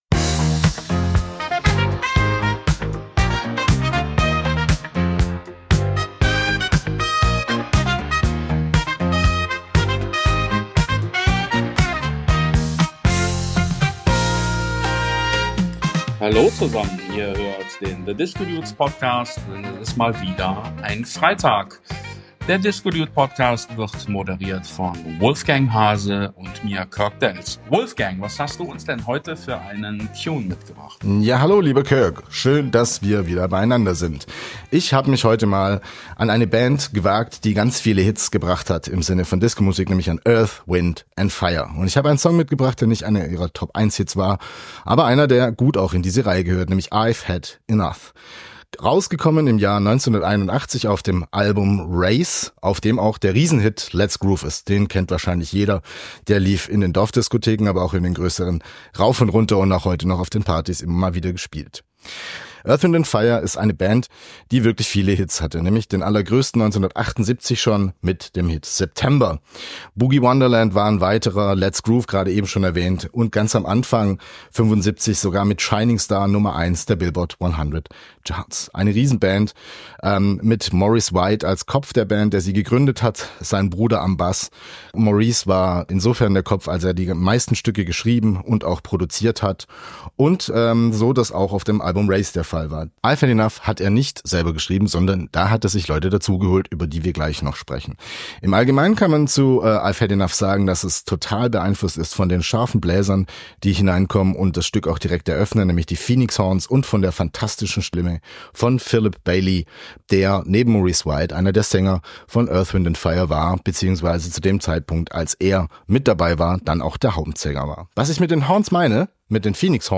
Jazz Funk goes Disco mal zwei - wie ein Funk-Drummer und eine Disco-Hit-Machine den Boogie-Dancefloor aufmischen - und das auch noch mit viel Liebe.